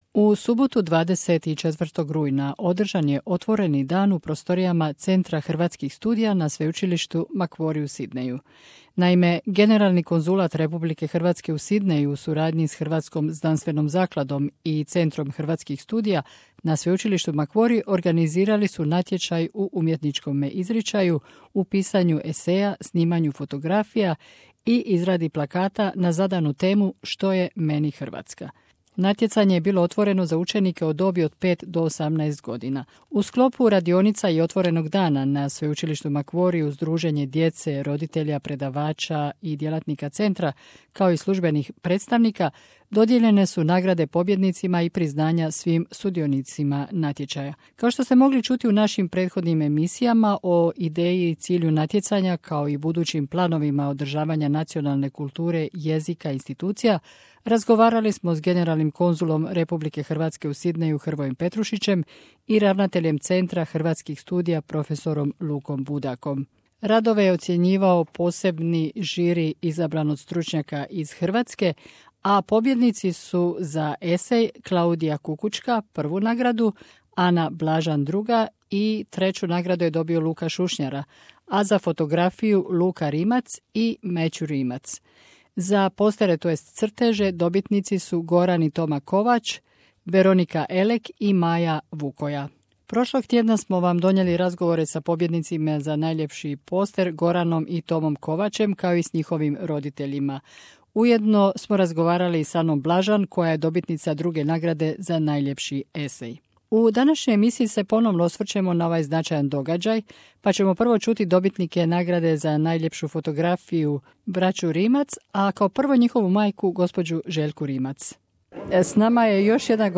U Centru hrvatskih studija na sveučilištu Macquarie pozvani su mladi hrvatskog podrijetla koji žive u Novom južnom Walesu da svojim literarnim i umjetničkim radovima za natječaj 'Što je meni Hrvatska' izraze svoj osjećaj identiteta i pripadnosti. Na svečanosti dodjele nagrada razgovarali smo s nekim dobitnicima nagrada.